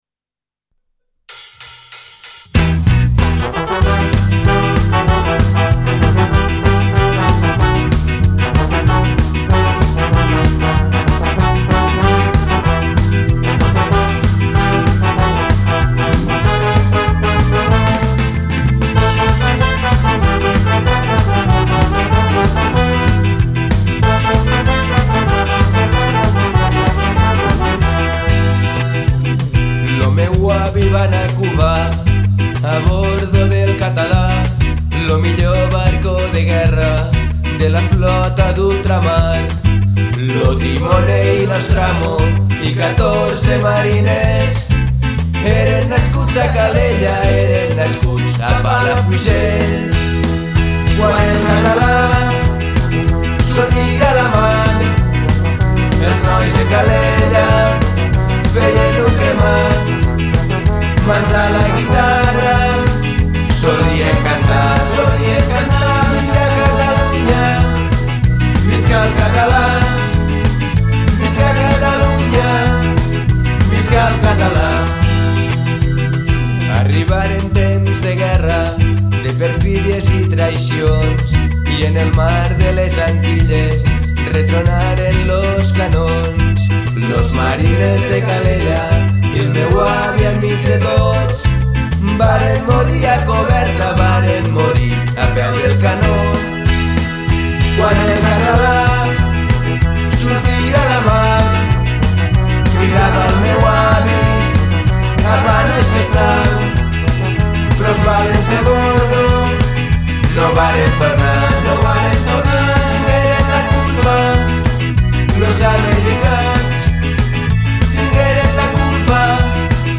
una versió que han fet d'una de les havaneres més conegudes.
coneguda pel seu ska, rock i to reivindicatiu
el transforma en una cançó plena de ritme i bon humor